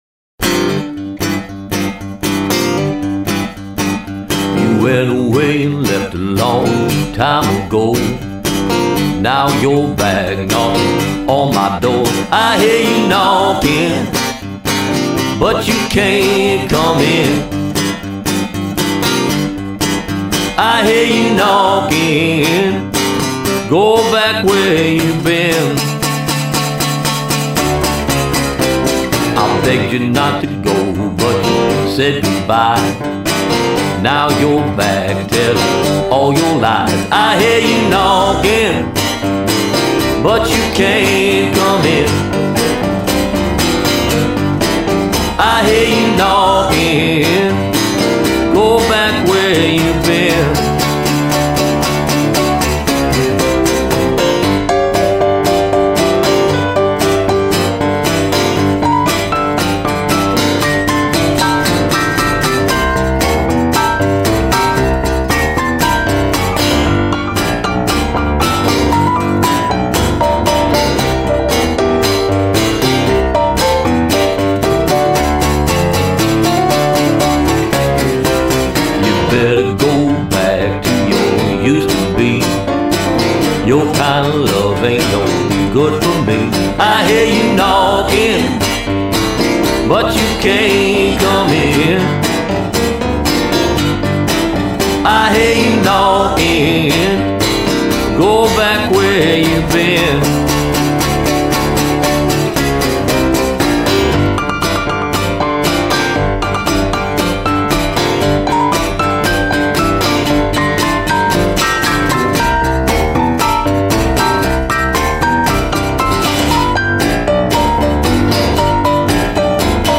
Vocal Stylist - Entertainer